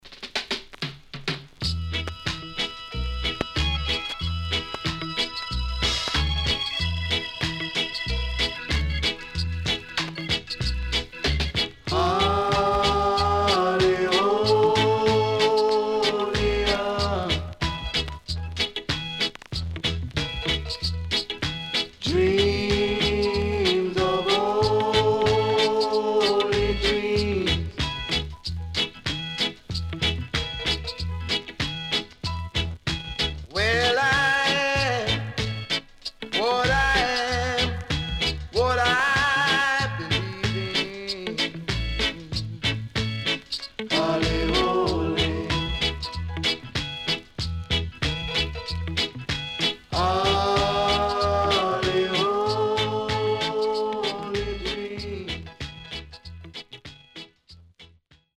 HOME > REGGAE / ROOTS
CONDITION SIDE B:VG(OK)
SIDE B:所々チリノイズがあり、少しプチノイズ入ります。